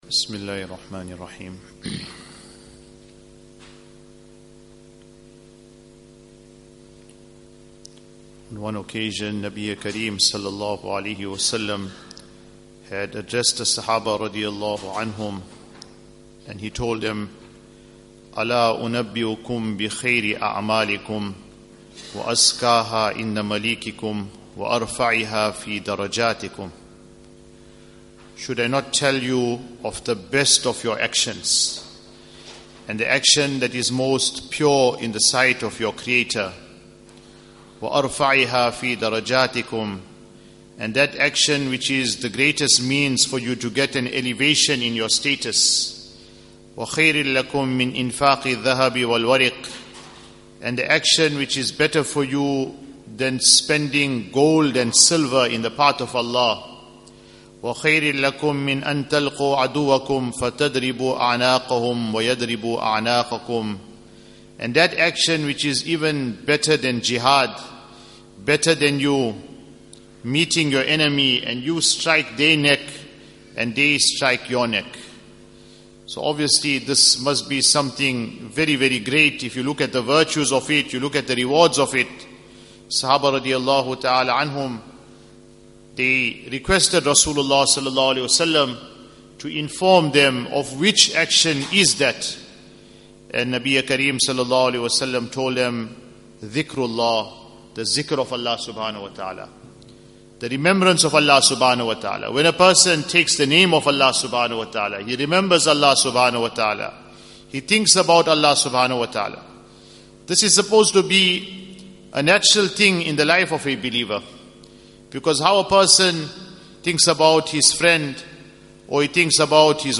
Morning Discourses